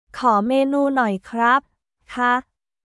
コー メーヌー ノイ クラップ／カー